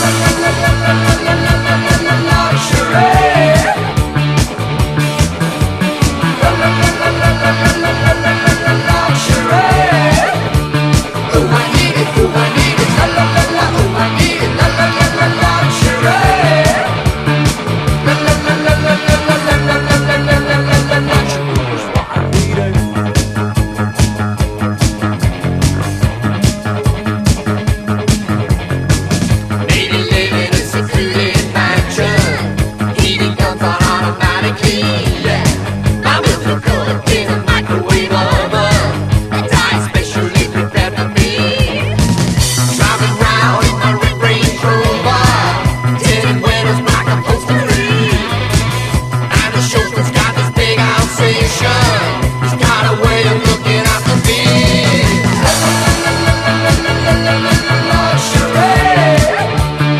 ¥580 (税込) ROCK / 80'S/NEW WAVE.
ELE POP / SYNTH POP
エレクトリック・ホワイト・ファンク/エレポップな名曲満載！